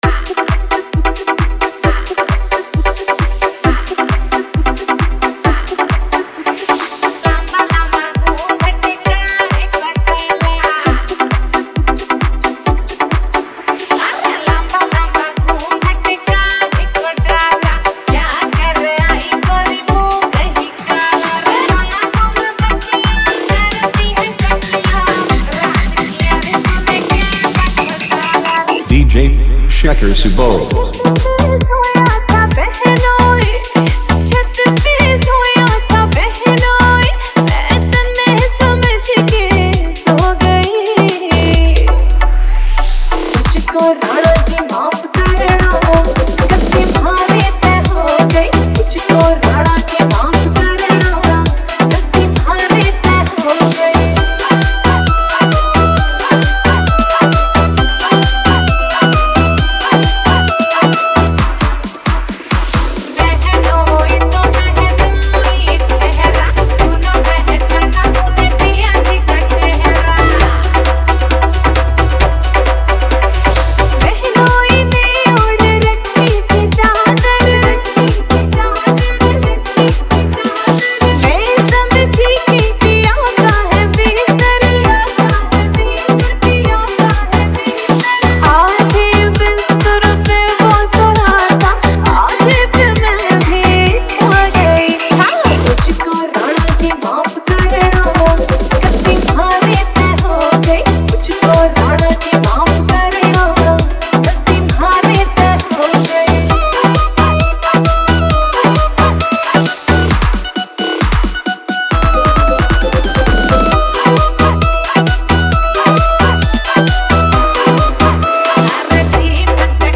Haryanvi DJ Remix Songs